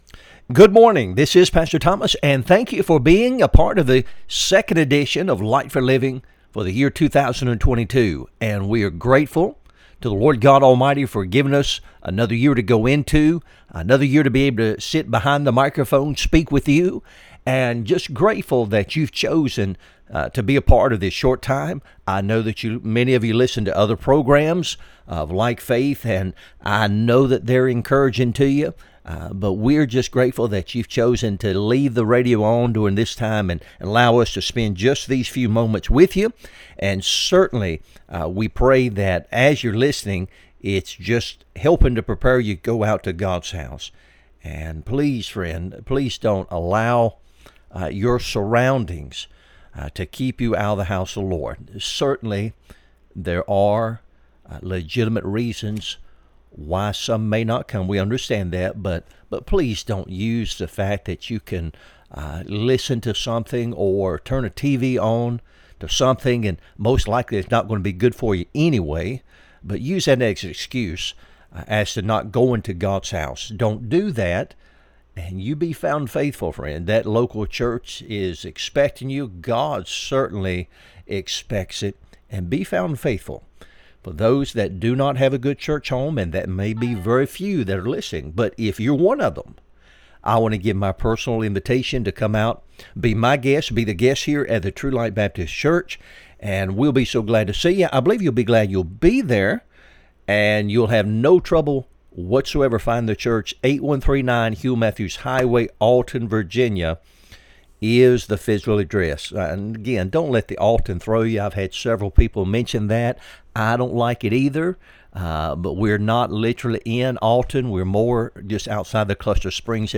Sermons | True Light Baptist Church of Alton, Virginia
Light for Living Radio Broadcast